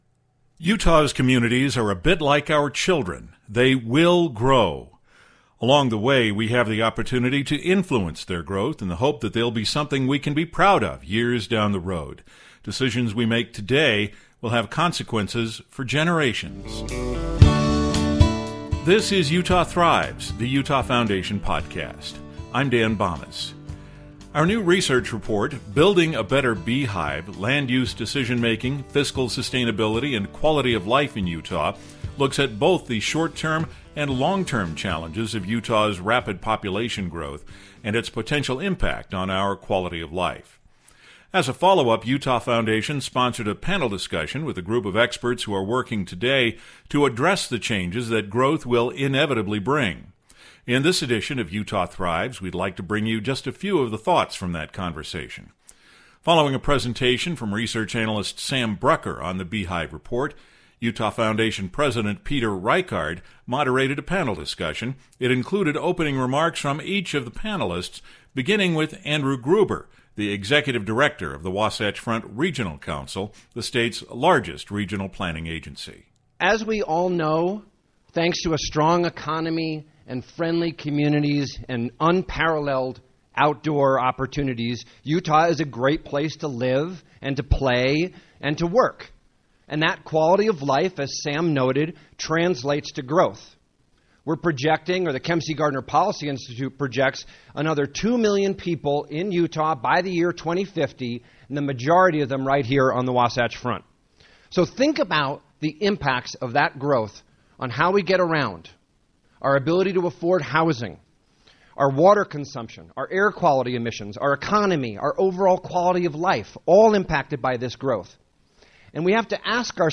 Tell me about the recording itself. In this edition of Utah Thrives, we’ll hear a few of the thoughts from that conversation, presented June 20, 2019 at the Zions Bank Founder’s Room.